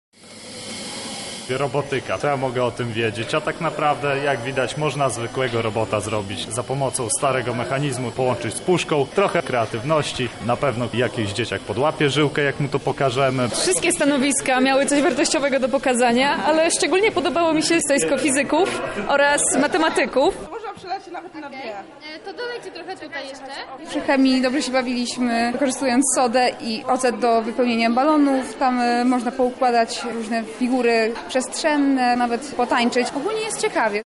student geoinformatyki i wolontariusz Projektora.